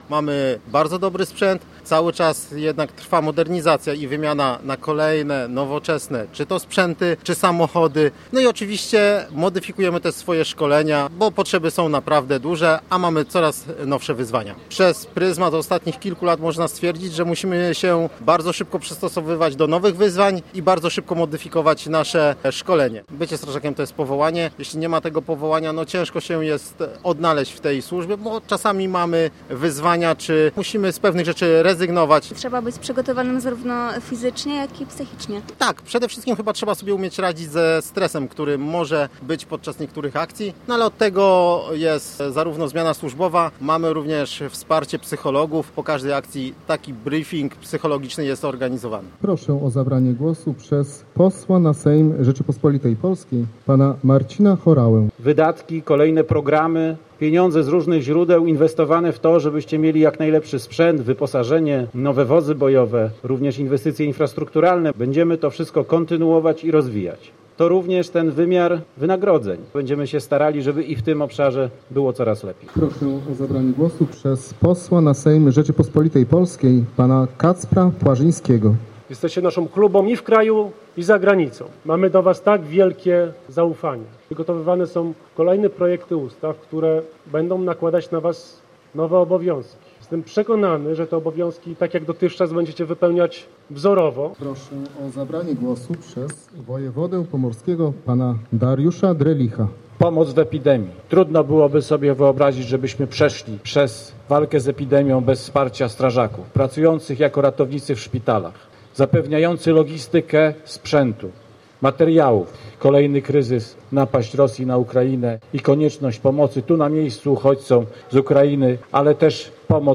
31 maja w Gdańsku odbyły się wojewódzkie obchody Dnia Strażaka.
Posłuchaj materiału naszej reporterki: